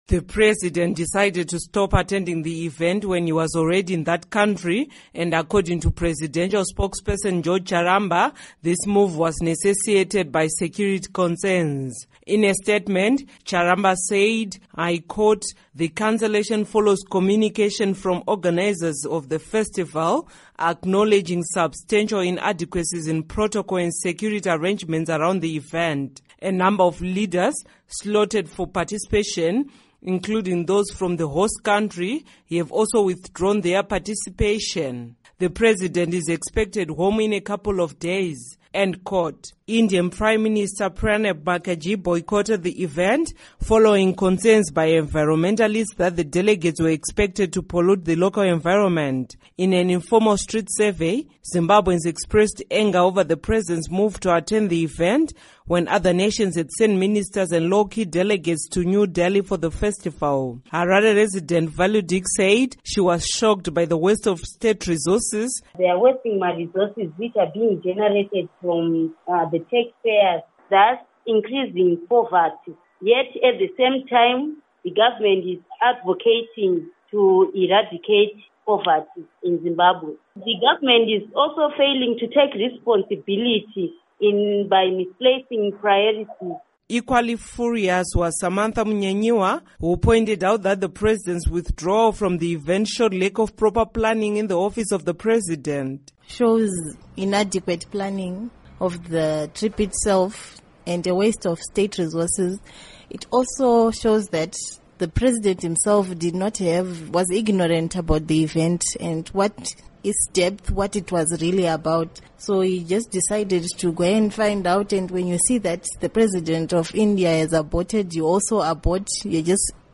In an informal street survey, Zimbabweans expressed anger over the president’s move to attend the event when other nations had sent ministers and low key delegates to New Delhi for the festival.